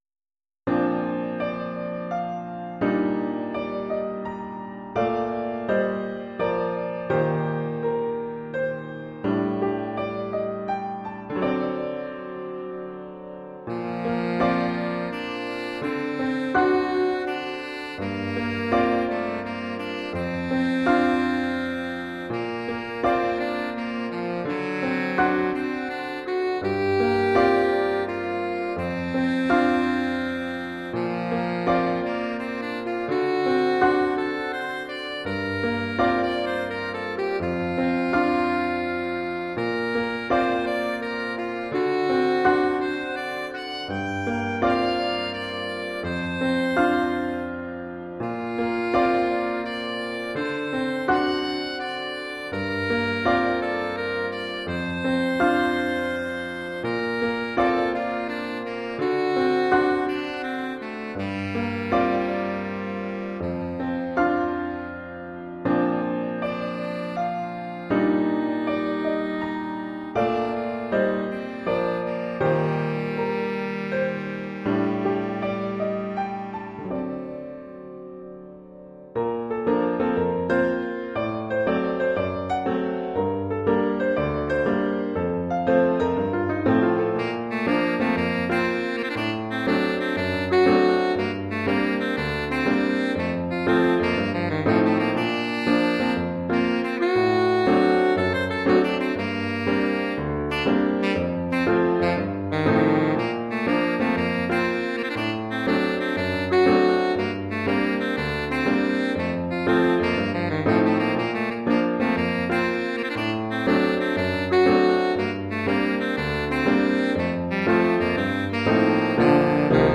Saxophone alto Mib
Oeuvre pour saxophone alto et piano.